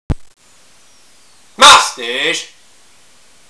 moustache.wav